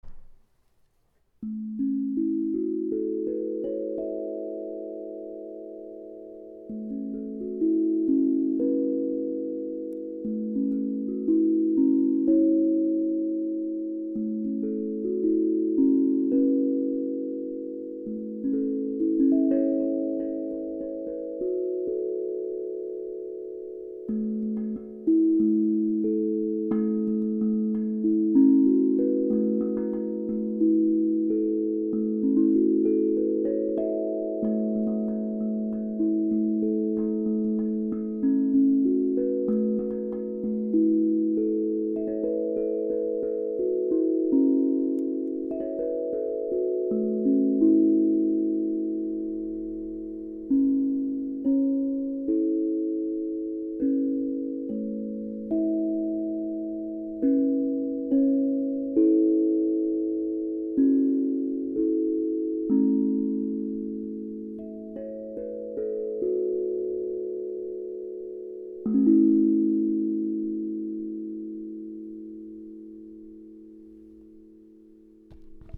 Douceur étoilée La 432 hz sur 8 notes - Osb Drum
Gamme de La Joyeux :) La gravure est une totale création en lien avec l'hexagone du flocon de neige et le féminin sacré.